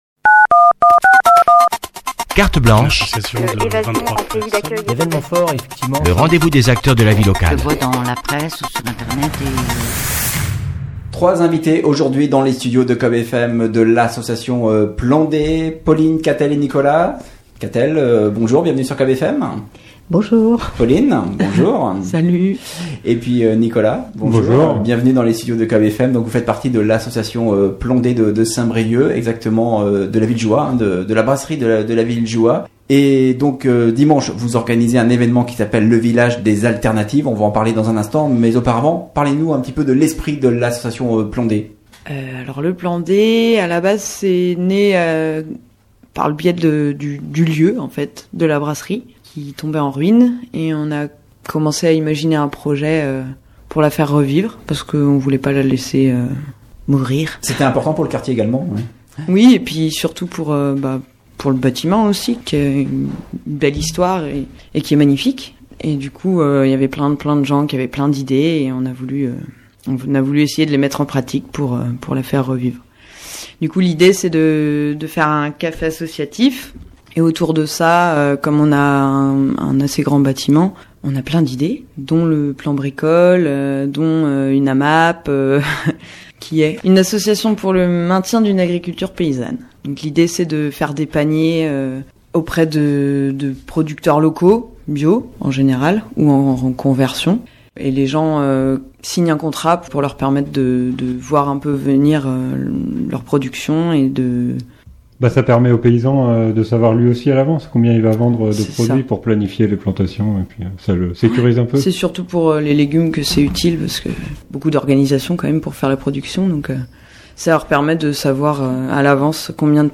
Dimanche à Saint-Brieuc, l’association Plan D organise le Village des alternatives à l’ancienne Brasserie de la Ville-Jouha devenue un éco-lieu culturel, alternatif et participatif; pour nous en parler, 3 de ses bénévoles